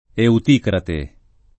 Euticrate [ eut & krate ]